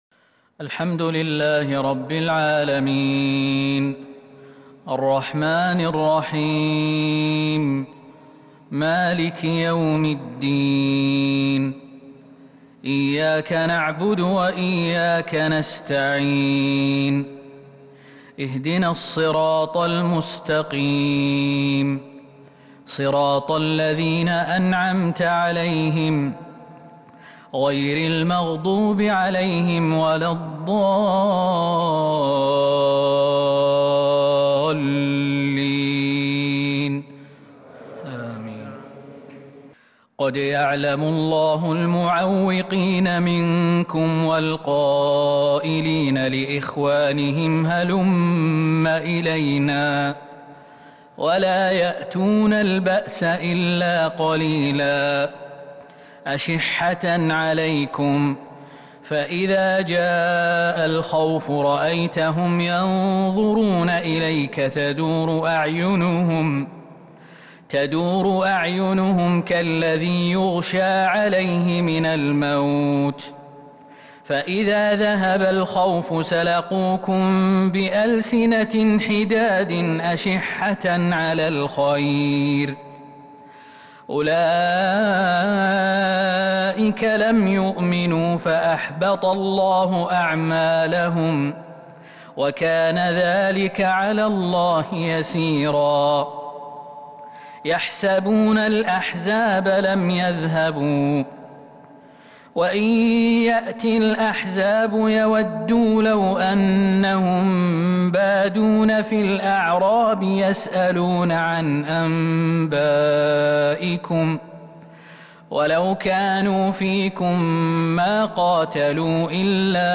فجر الأحد 24 ذي القعدة 1442هـ من سورة الأحزاب | Fajr prayer from Surat Al-Ahzab 4-7-2021 > 1442 🕌 > الفروض - تلاوات الحرمين